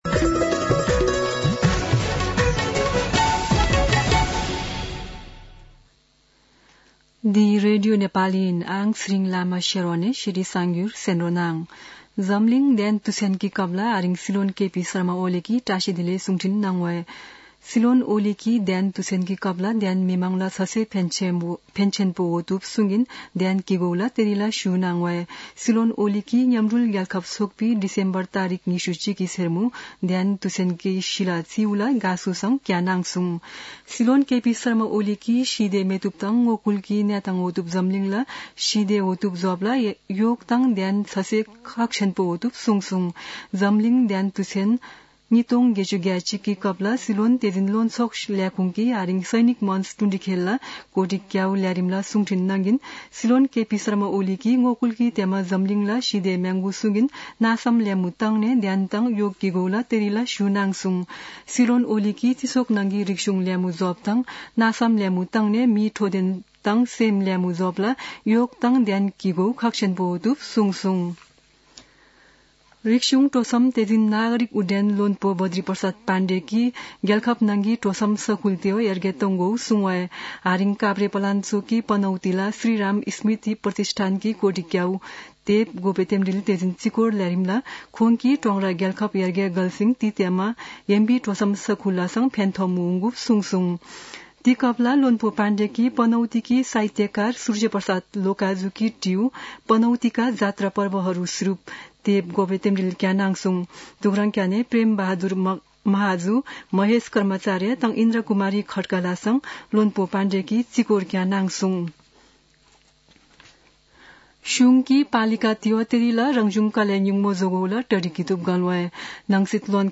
शेर्पा भाषाको समाचार : ७ पुष , २०८१
Sherpa-News-1.mp3